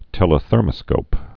(tĕlə-thûrmə-skōp)